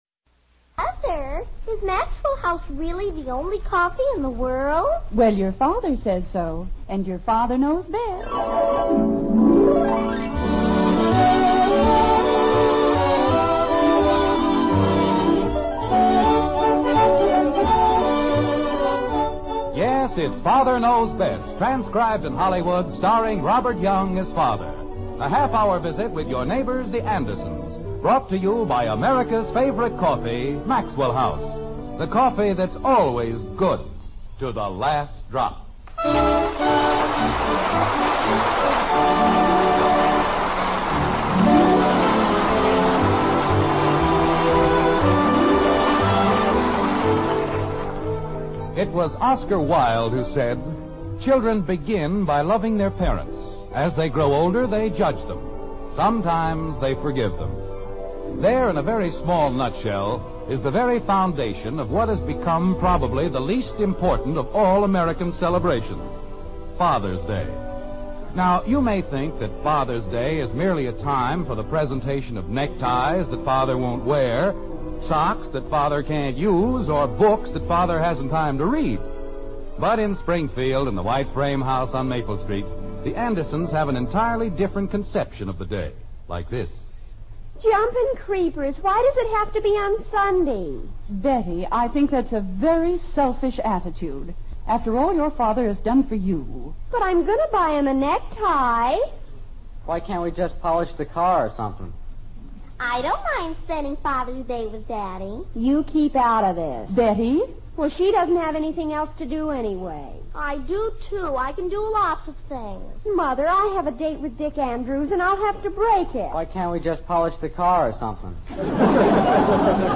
Father Knows Best was a hit on radio before making it's way to television.